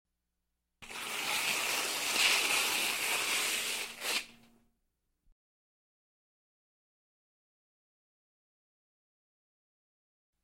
газеты звуки скачать, слушать онлайн ✔в хорошем качестве
Газета: переворачивают страницы, сворачивают, газету бросают и поднимают Скачать звук music_note Бумага , газеты save_as 951.9 Кб schedule 1:01:00 3 2 Теги: wav , бумага , бумажные изделия , газета , закрыли , звук , Книга , открыли , Страницы